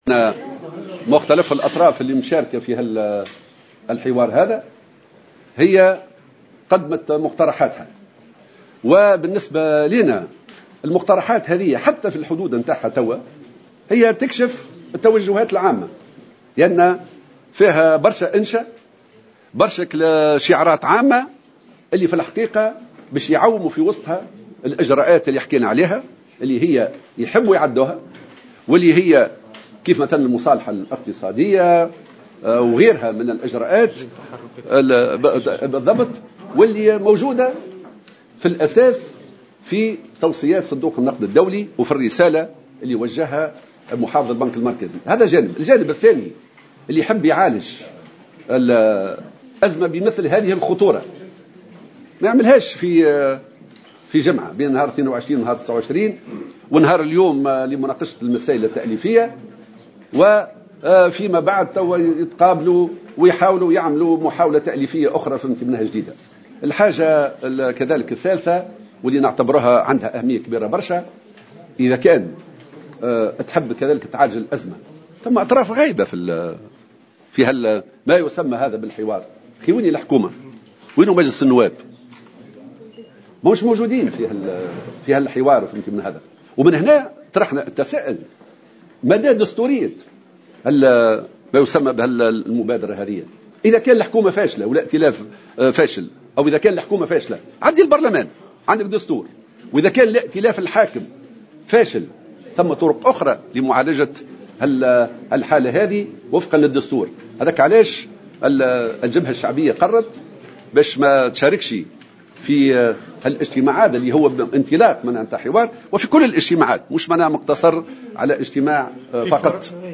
وكشف في ندوة صحفية الوثائق التأليفية لمقترحات مختلف الأطراف المشاركة في مشاورات حكومة الوحدة الوطنية والتي تتضمن مشاريع تعمل على الالتفاف على مكاسب الثورة التونسية من بينها حرية التعبير، بحسب تعبيره.